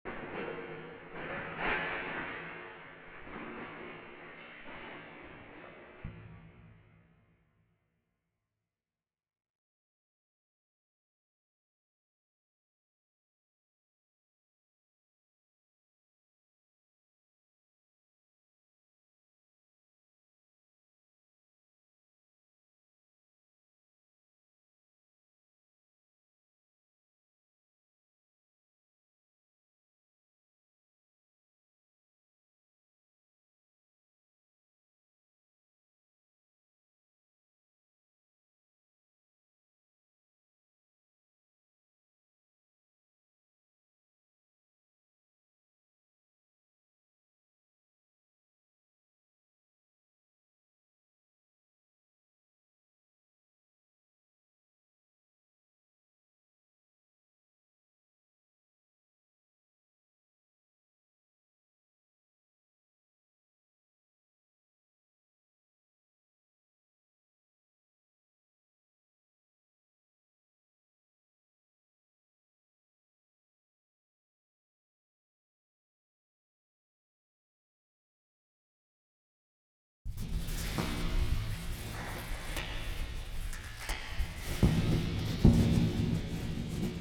A segment of "Glass Break 1" audio, with a spatial audio effect, as well as an audio effect titled 'shimmery' added to it. This sound is correlated with the letter "i" on the computer keyboard.
Edited with and exported from Abletone Live.